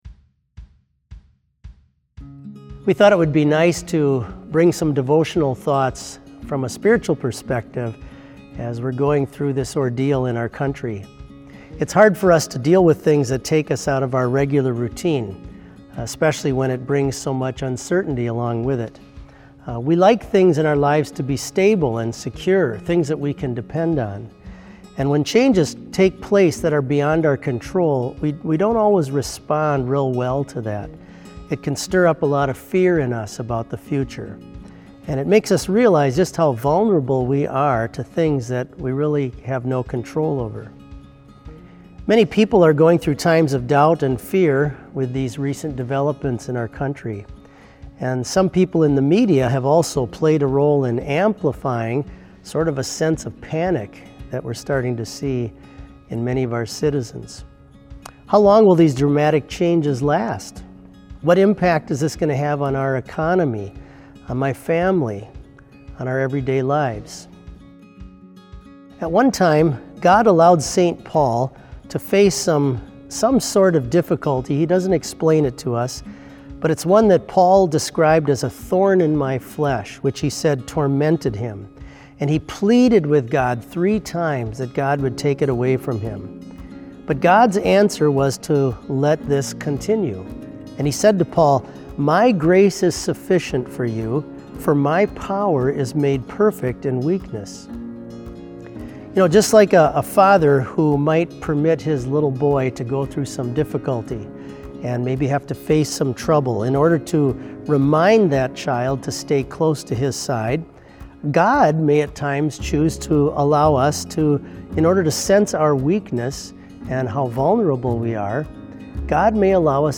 Complete service audio for BLC Devotion - March 16, 2020